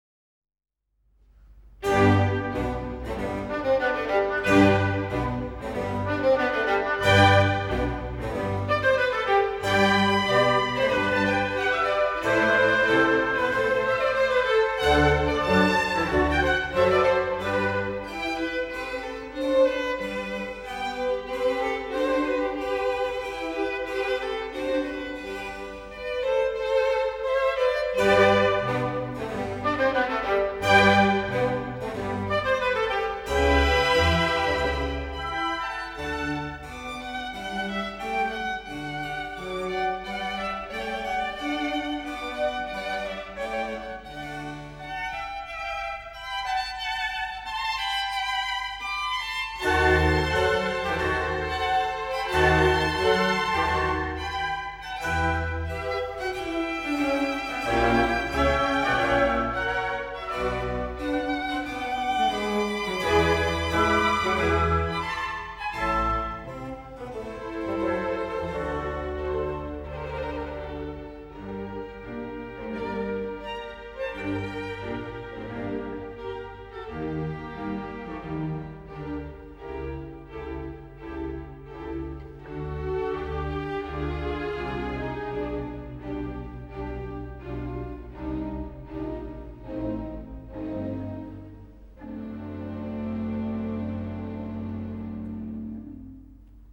in G major - A tempo giusto